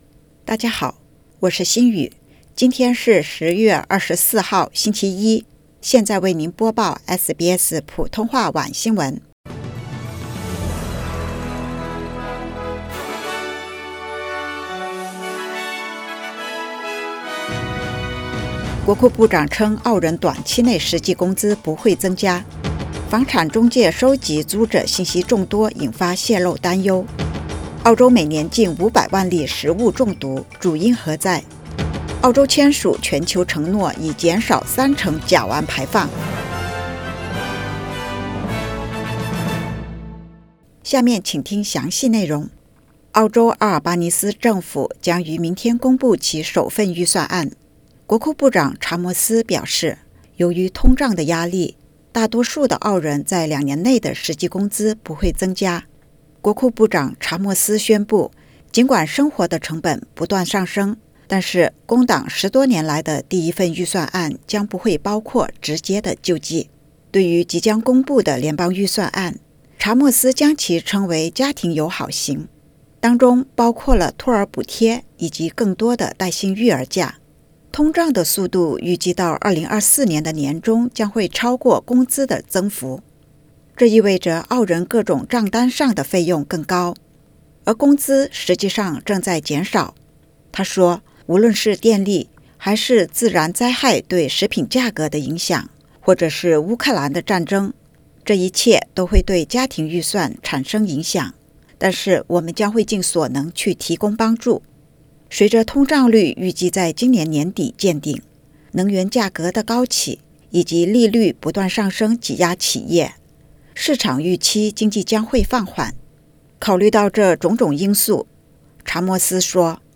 SBS晚新闻（2022年10月24日）